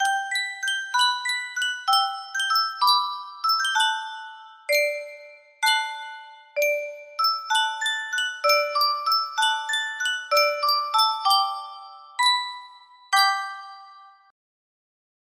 Yunsheng Music Box - The Band Played On Part 1 6817 music box melody
Full range 60